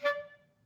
Clarinet / stac
DCClar_stac_D4_v2_rr1_sum.wav